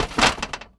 metal_hit_small_06.wav